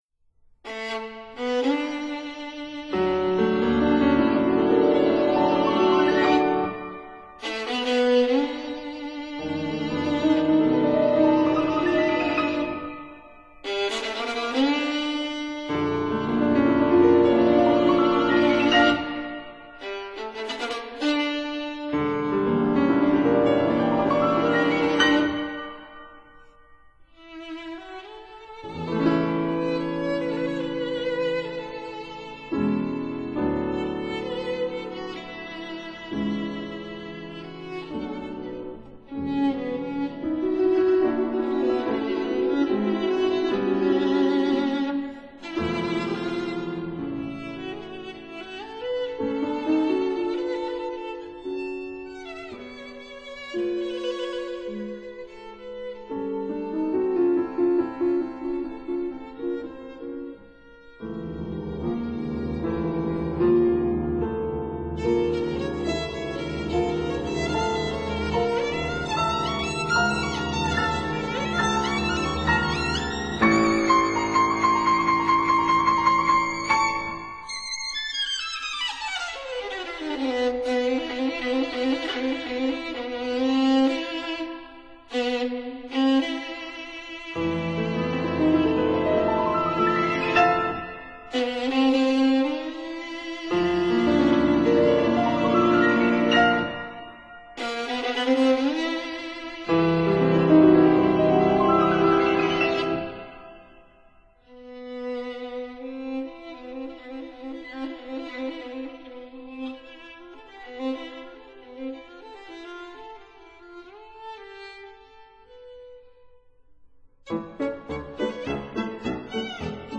piano
violin
viola
cello